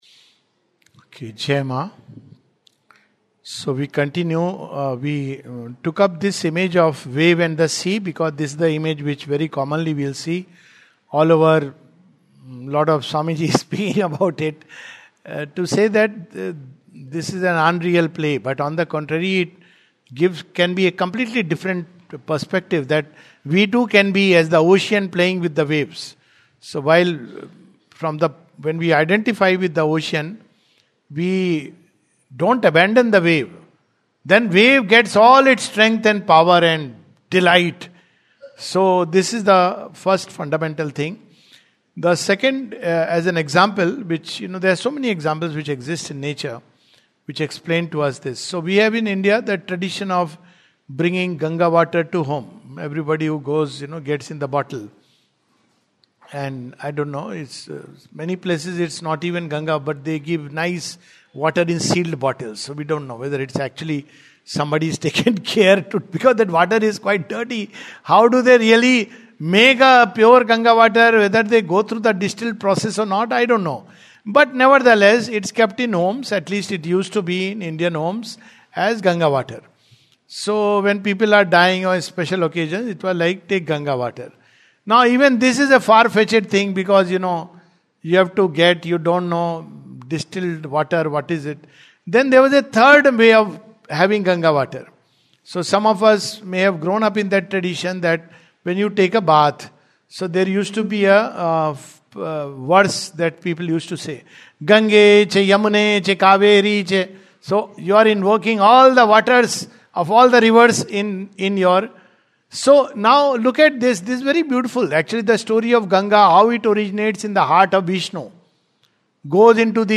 The Life Divine, 23rd February 2026, Session # 06-04 at Sri Aurobindo Society, Pondicherry, India. We take up the famous snake and rope analogy and its limitations.